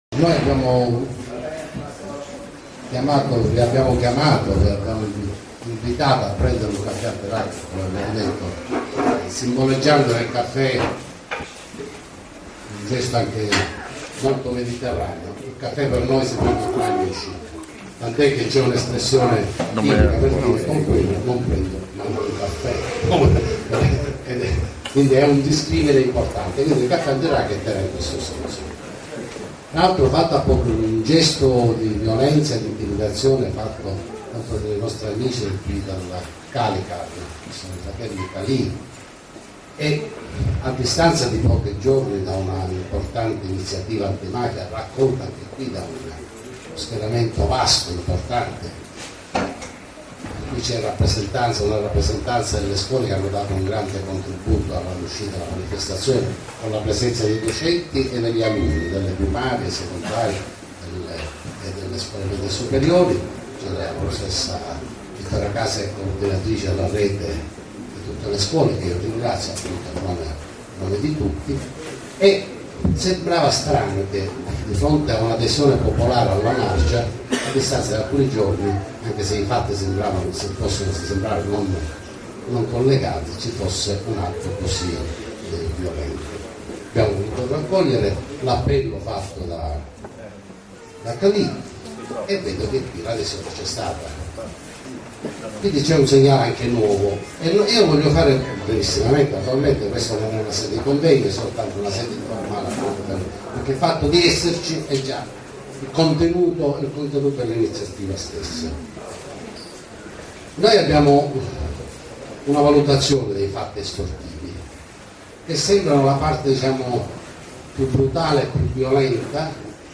ore 17 presso il Centro Esposizione Calicar (via Gelsi Neri, svincolo autostradale di Altavilla Milicia - Pa)